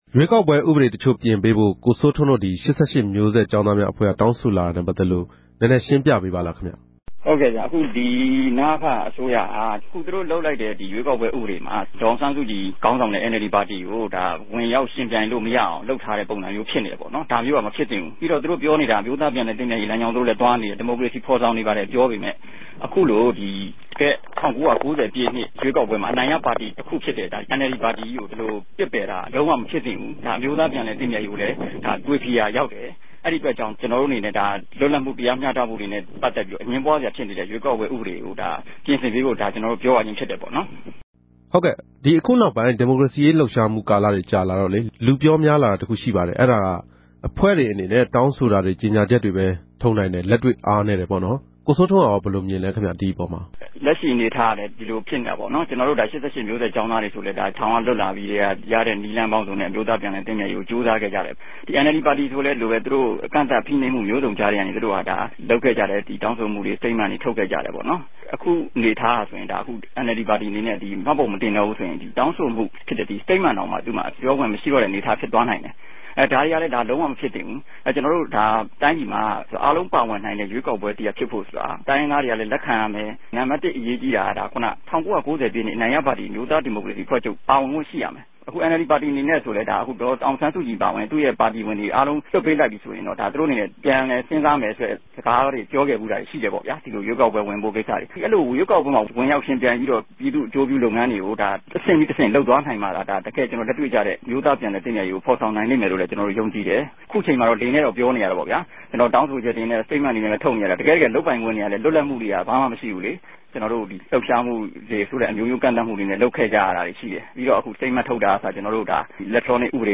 ဆက်သြယ်မေးူမန်းခဵက်။